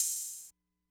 Metro OPenhats [Dusty].wav